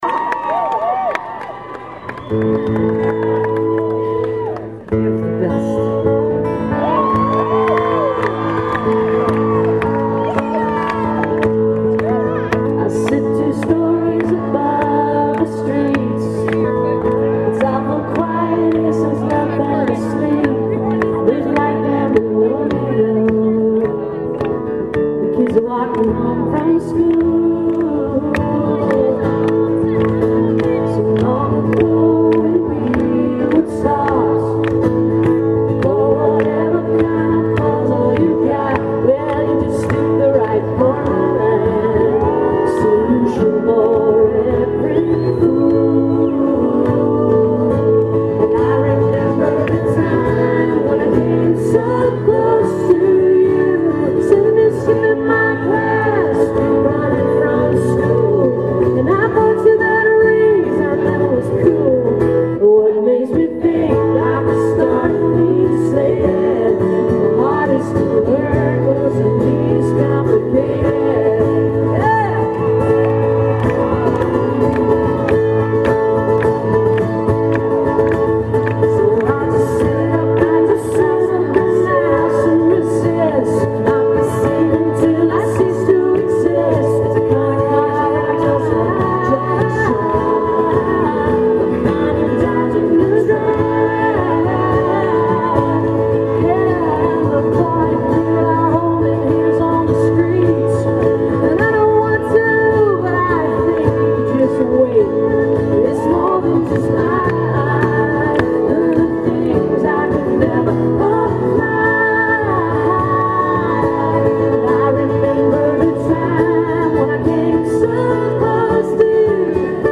(acoustic duo show)